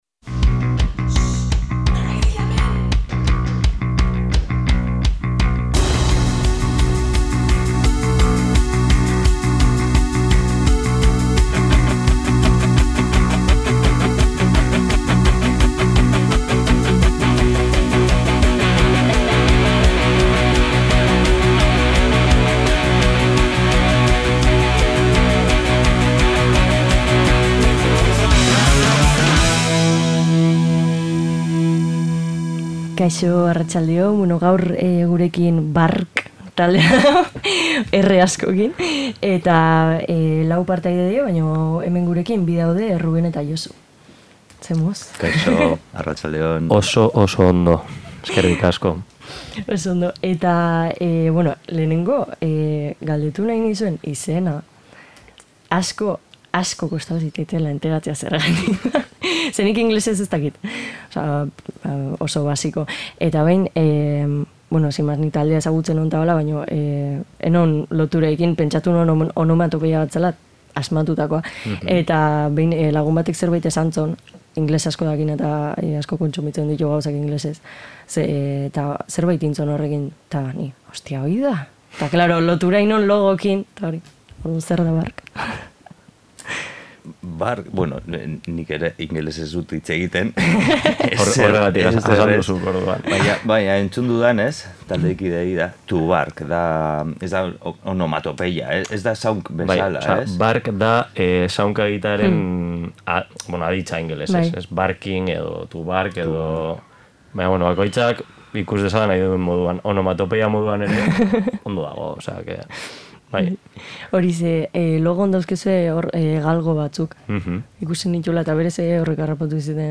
Pixka bat denetarik irten da elkarrizketan, eta aurreko saioetan hasitako galdera zerrendarekin jarraitu dugu, giro onean. Musika zerrenda ederra sortu da gainera, etorri ezin izan diren taldekideen gomendioekin batera.